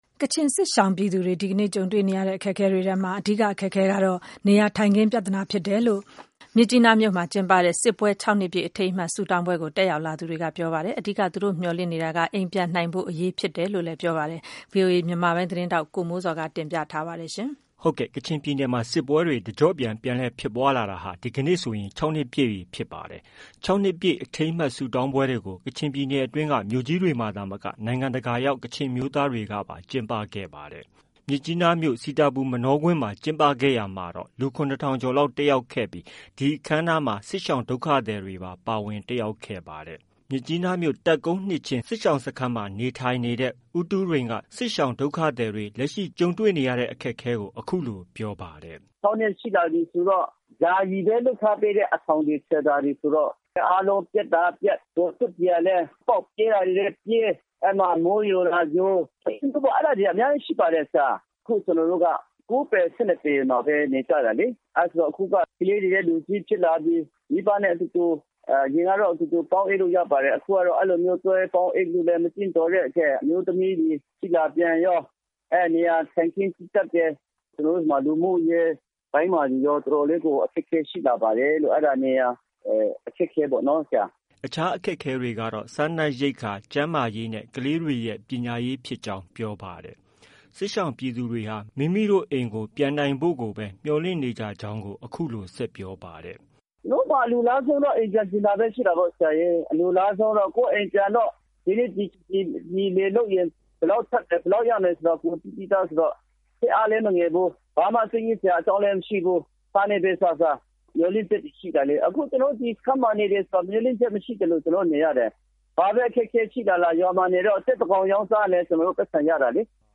ကချင်စစ်ရှောင်ပြည်သူတွေ ဒီကနေ့ ကြုံတွေ့နေရတဲ့ အခက်အခဲတွေထဲမှာ အဓိက အခက်အခဲဟာ နေရာထိုင်ခင်း ပြဿနာဖြစ်တယ်လို့ မြစ်ကြီးနားမြို့မှာ ကျင်းပတဲ့ စစ်ပွဲ၆နှစ်ပြည့် အထိမ်းအမှတ်ဆုတောင်းပွဲကို တက်ရောက်လာသူတွေက ပြောပါတယ်။